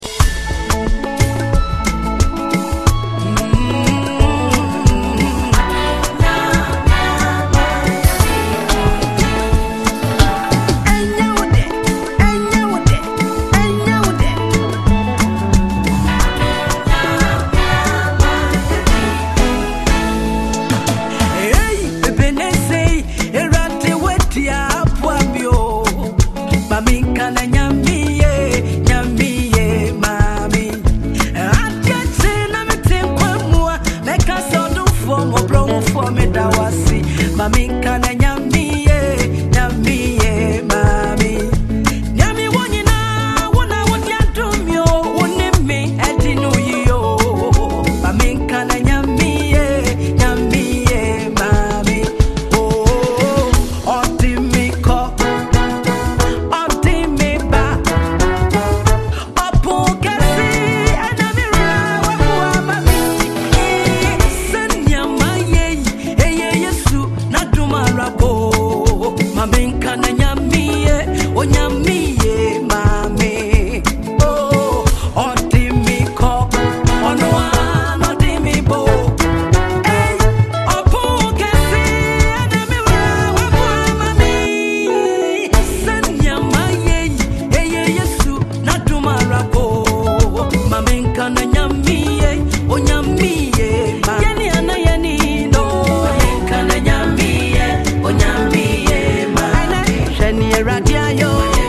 gospel
whose touch blends modern soundscapes with rich gospel depth
a contemporary feel and a timeless worship atmosphere
Through her commanding vocals